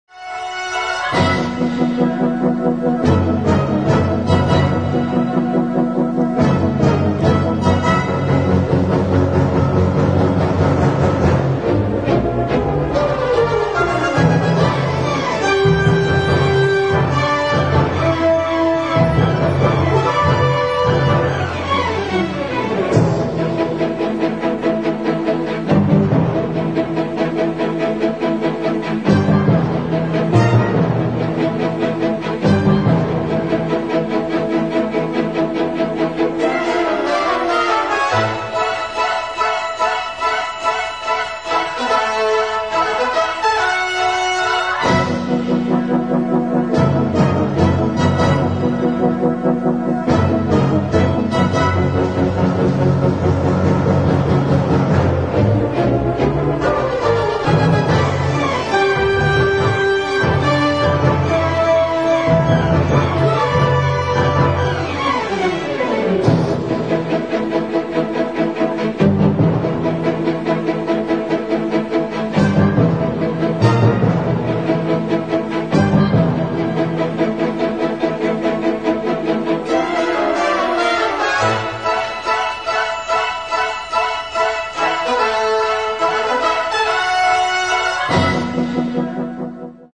Tags: epcot horizons audio clips themepark audio